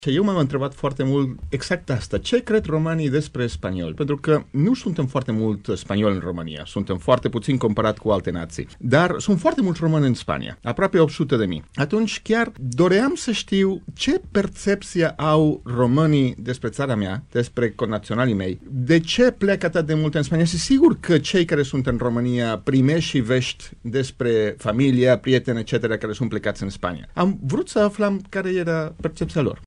Prezent în emisiunea Vestul Zilei de la Radio Timișoara, consulul onorific al Spaniei în România, Jose Manuel Viñals a declarat că motivul acestui demers a fost aflarea percepției românilor despre Spania: